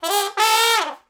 MANNIE_FRESH_trumpet_second_line_growl.wav